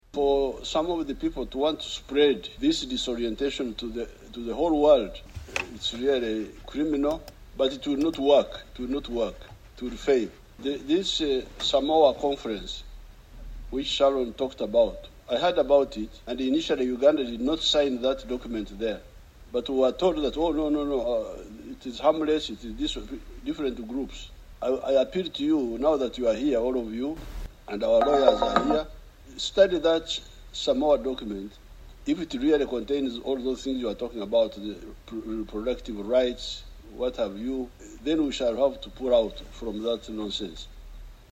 President Yoweri Museveni, addressing the gathering, voiced strong support for the cause, warning against what he called “disorientation” being pushed on African societies.